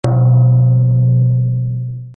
gong.mp3